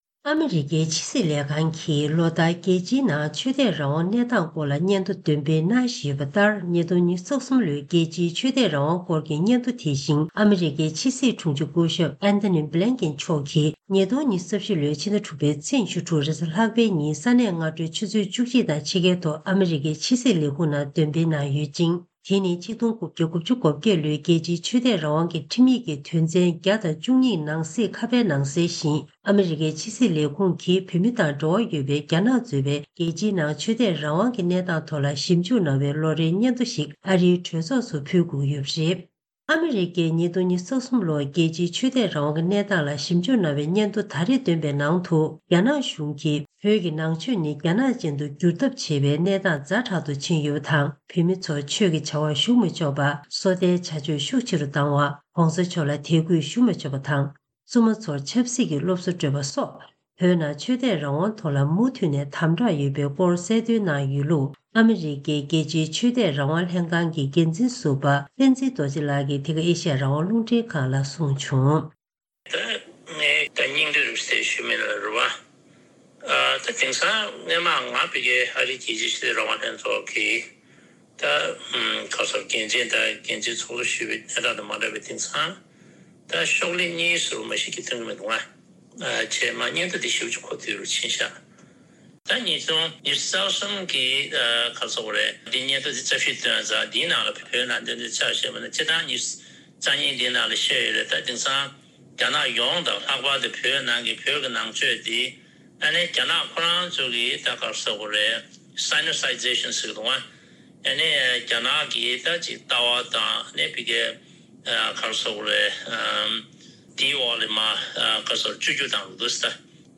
སྒྲ་ལྡན་གསར་འགྱུར། སྒྲ་ཕབ་ལེན།
ཐེངས་འདིའི་གསར་འགྱུར་དཔྱད་གཏམ་གྱི་ལེ་ཚན་ནང་།